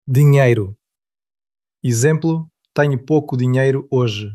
Pronunciación de la palabra